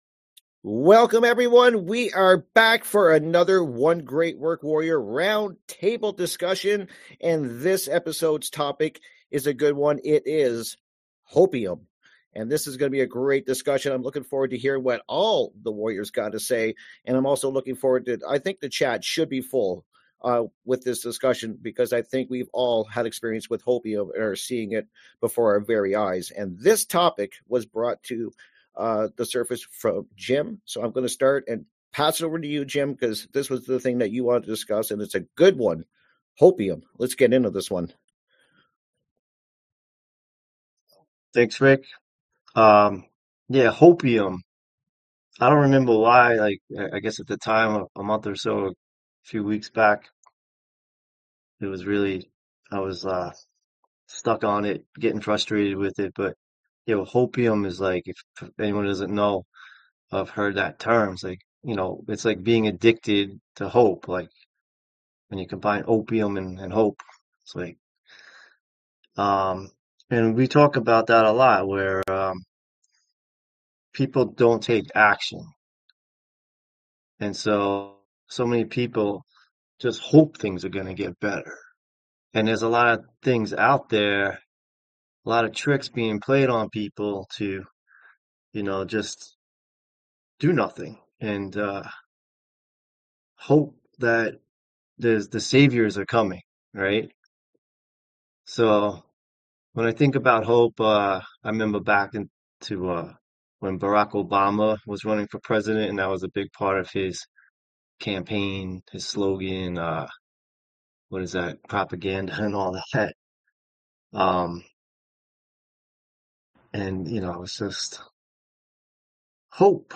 This roundtable talk is all about What It Takes To Be A Man. As we are all trying to discover the best path forward in a world that is backward and immoral, how do we emerge as strong powerful versions of what we know that we need to be, and how to get there!